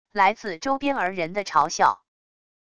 来自周边儿人的嘲笑wav音频